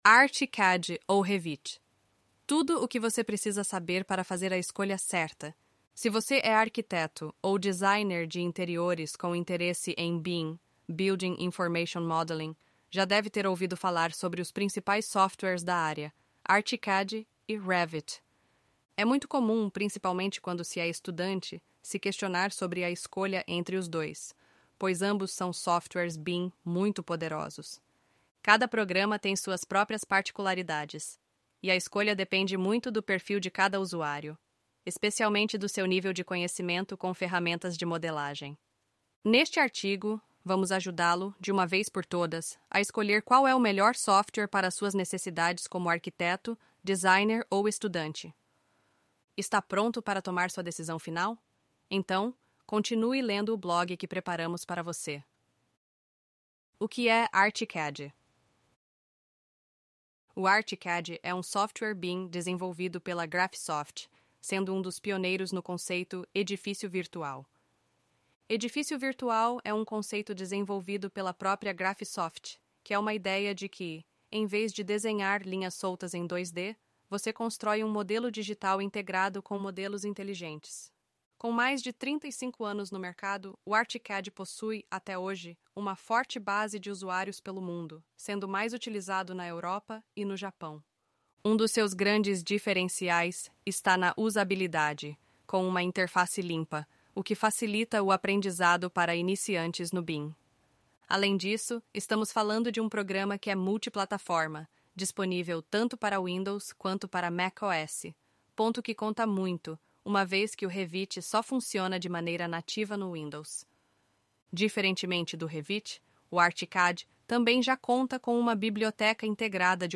Sem tempo para ler? Então, dê um play e escute nosso artigo!